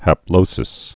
(hăp-lōsĭs)